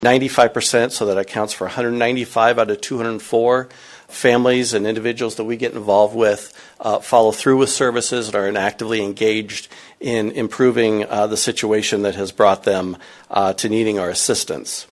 MPACT Quarterly Report at Marshalltown City Council Meeting | News-Talk 1230 KFJB